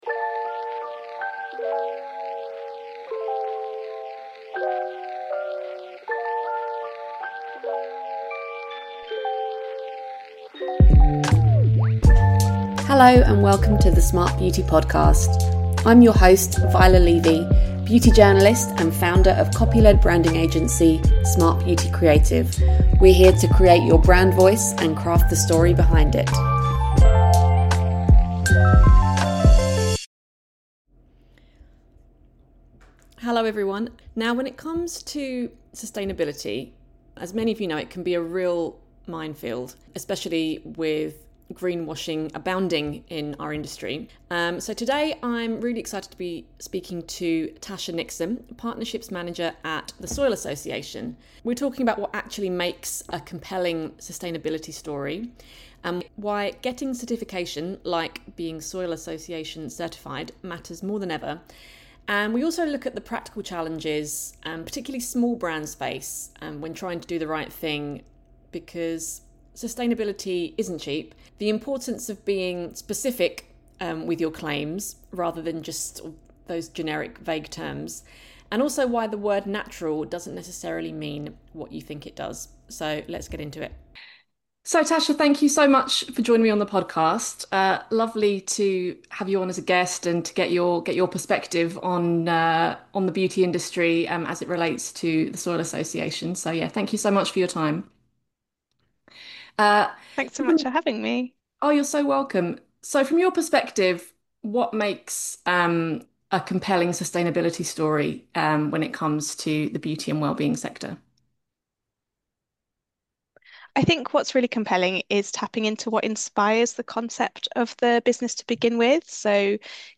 We also touch on the reality of costs for small brands, the "less but better" approach to consumption, and emerging trends around health and skin microbiomes. Whether you're building a beauty brand or simply curious about what's behind those green claims, this conversation offers practical guidance on navigating sustainability in an increasingly conscious market.